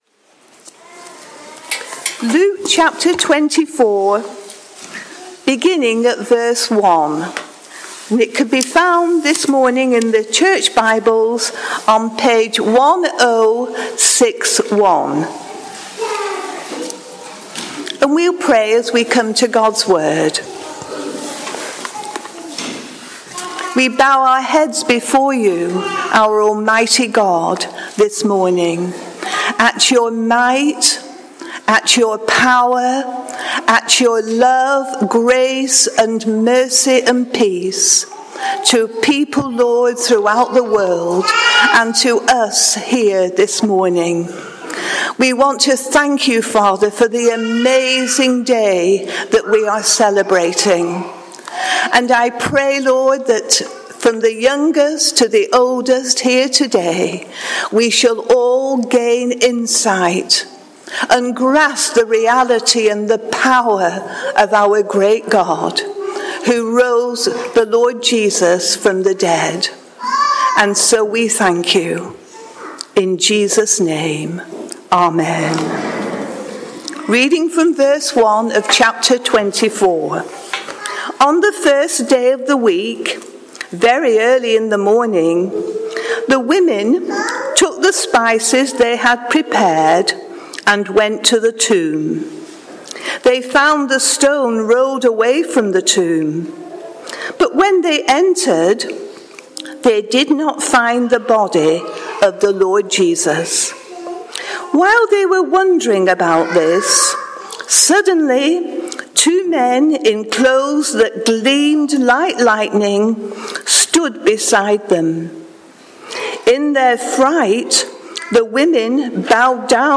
Luke 24: 1-12 – Easter All Age Service